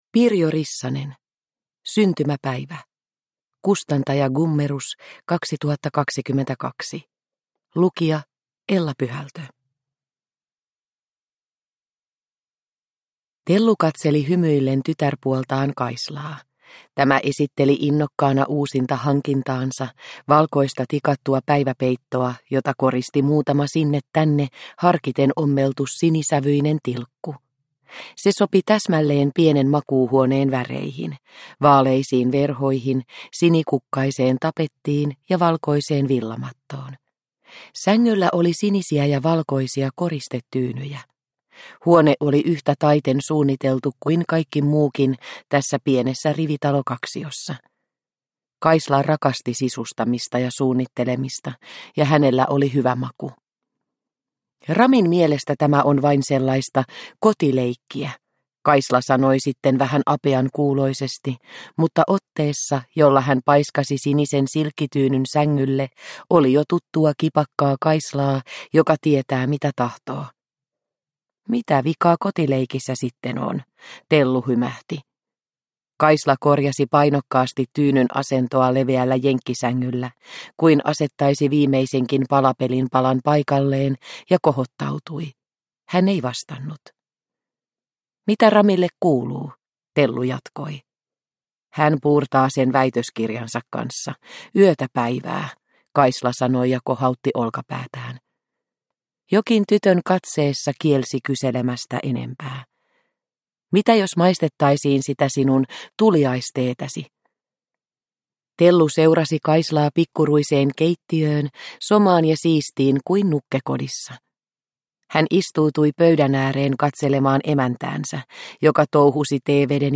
Syntymäpäivä – Ljudbok – Laddas ner